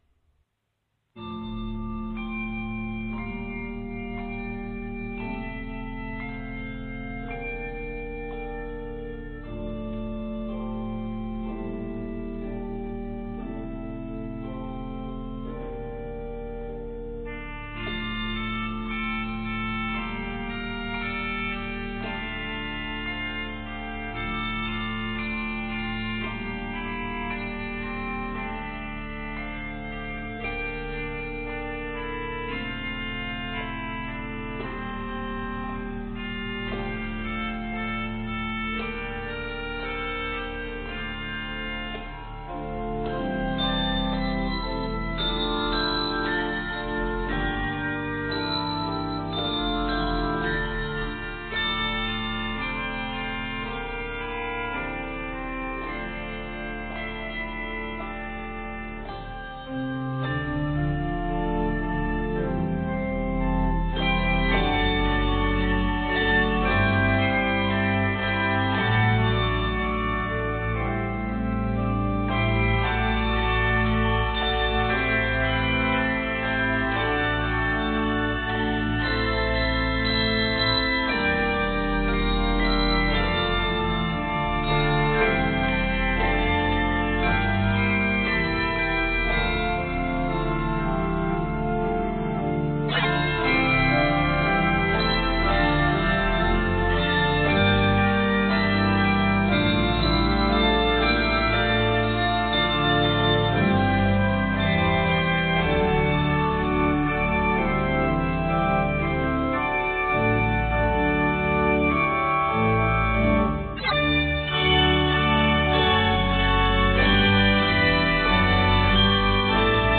The organ is a must! 4 titles
Octaves: 3-5